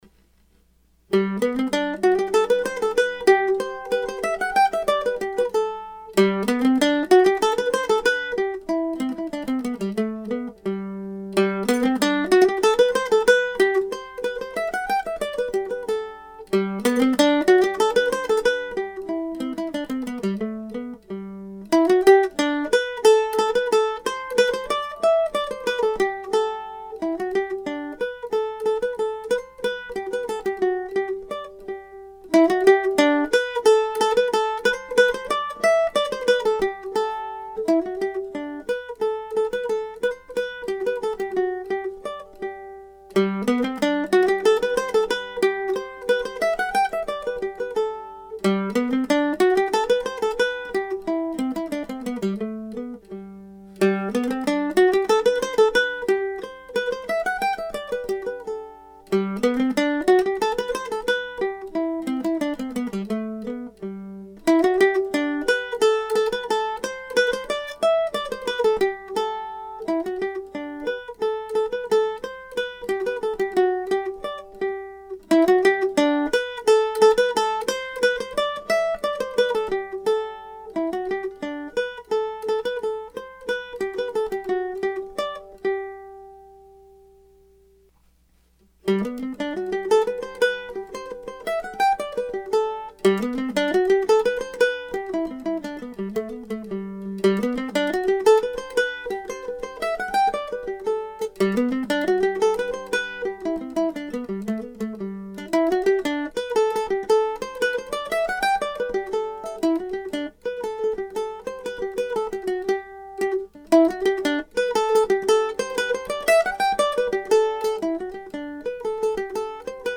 The second part is pretty much the same tune played as a jig, something that Oswald would often do. Part three is related but still has its own identity. Everything falls easily on the mandolin, of course it would also be comfy on the fiddle.